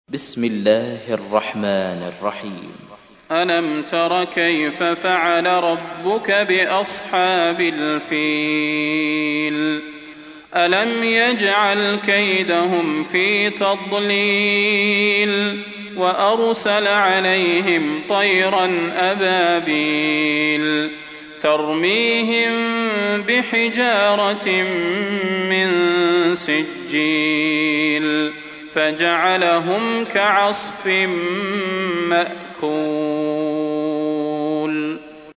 تحميل : 105. سورة الفيل / القارئ صلاح البدير / القرآن الكريم / موقع يا حسين